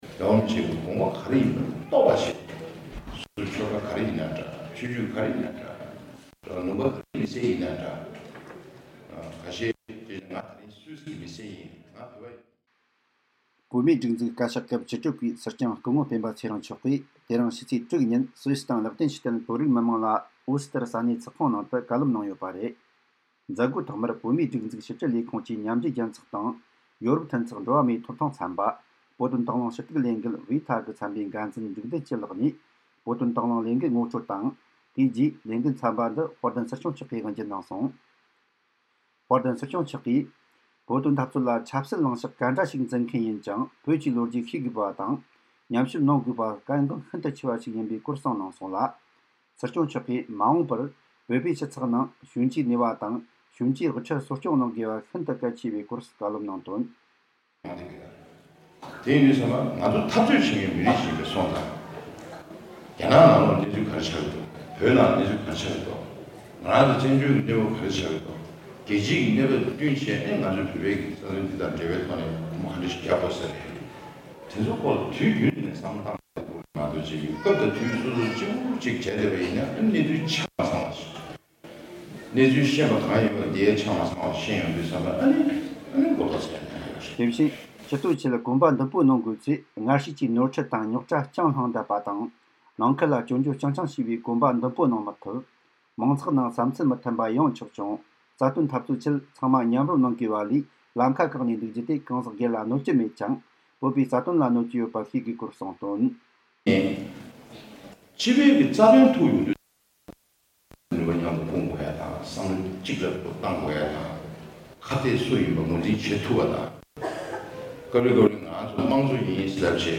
སྲིད་སྐྱོང་མཆོག་ནས་སུད་སི་དང་ལིག་ཏེན་སི་ཊན་བོད་རིགས་མི་མང་ལ་གསུང་བཤད།